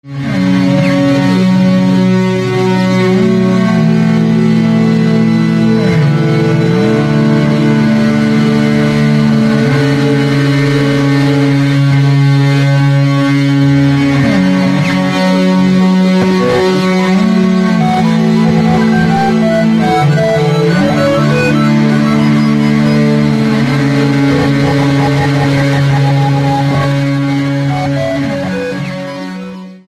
Электроакустические опыты